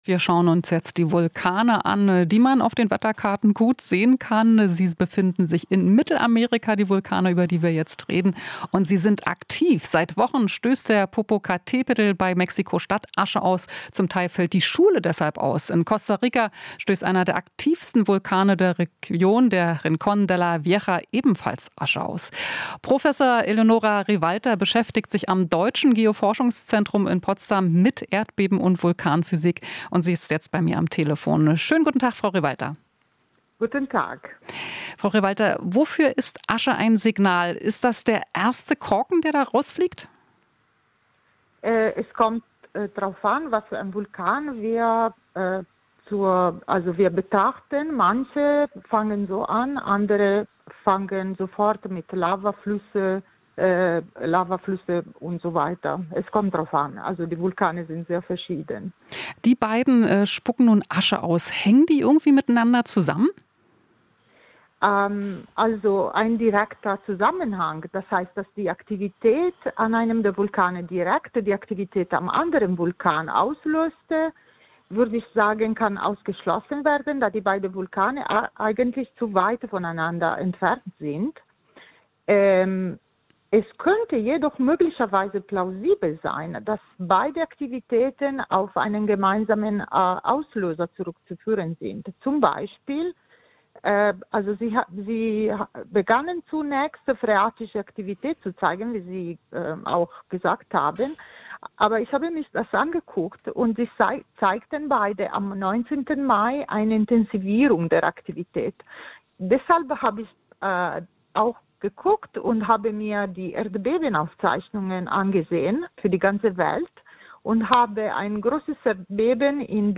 Inforadio Nachrichten, 05.08.2023, 16:00 Uhr - 05.08.2023